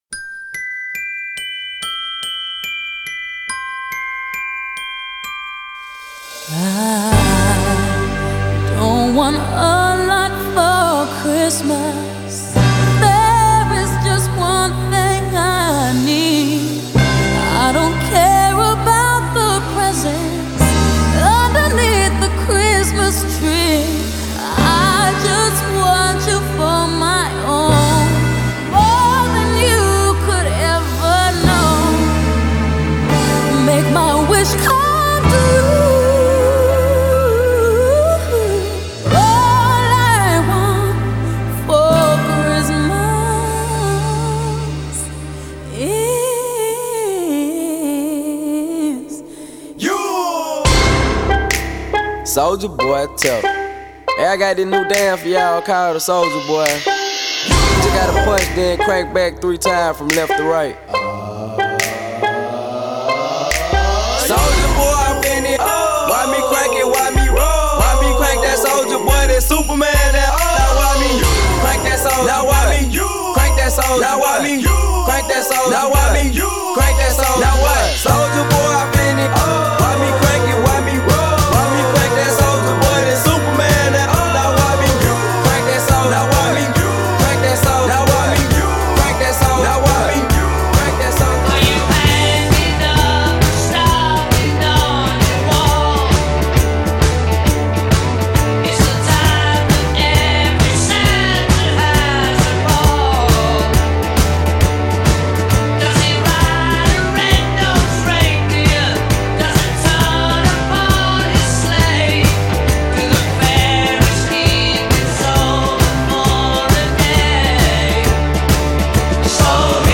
Here's a mashup troll edit I made many, many years ago.